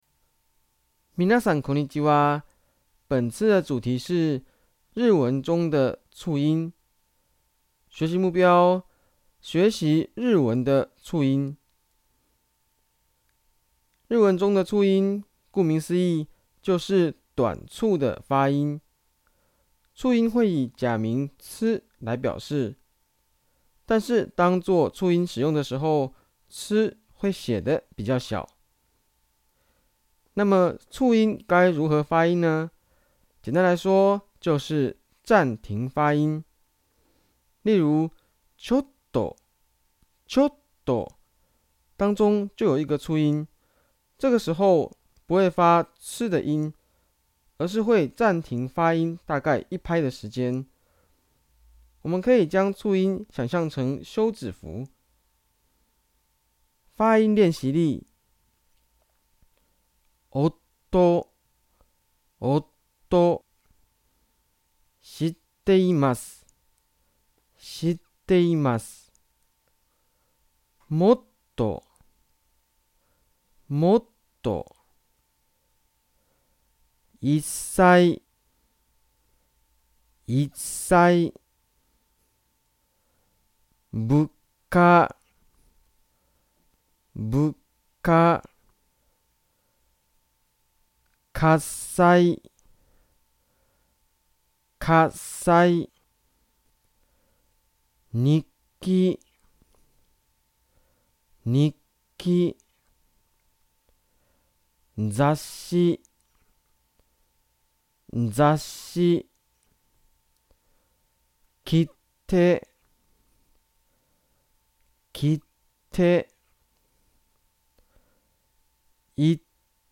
聲音解說：
促音如何發音呢? 簡單來說，就是「暫停發音」。
例如：「ちょっと」當中有一個促音，這時就不發「tsu」的音，而是突然暫停發音一拍的時間。